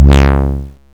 off, fly-out), engine/synth sound loops, thruster particles, and PNG
synth.wav